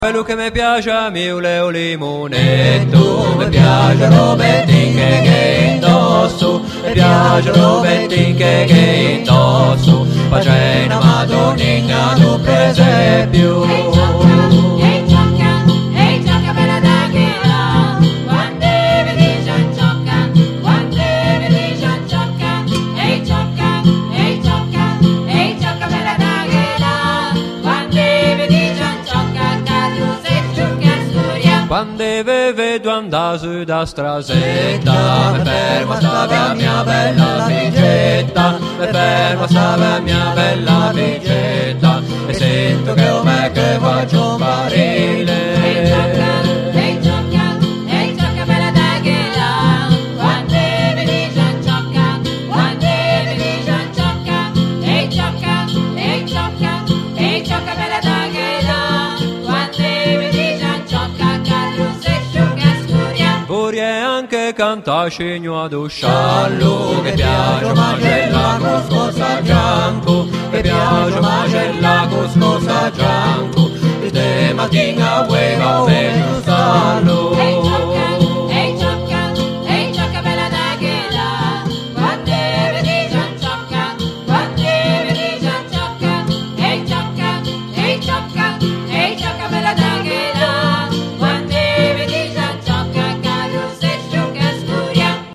Tralalêro zenéize